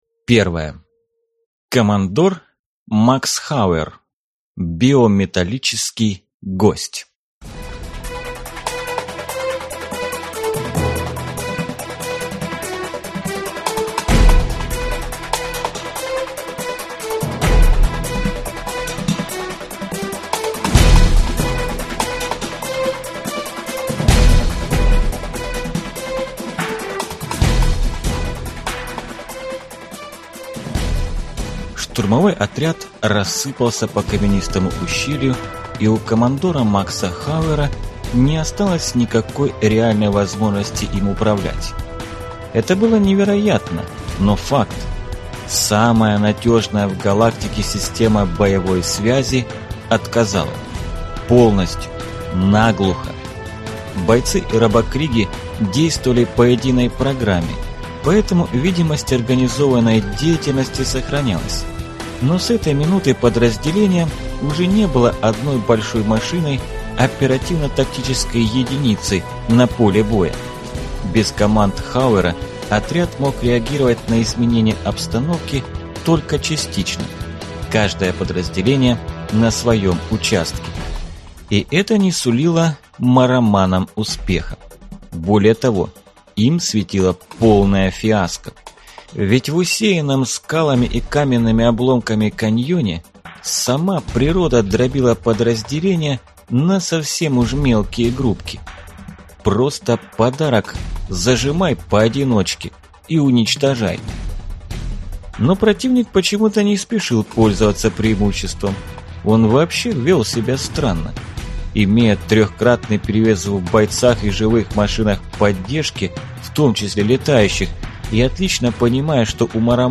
Аудиокнига Стальная бабочка | Библиотека аудиокниг
Прослушать и бесплатно скачать фрагмент аудиокниги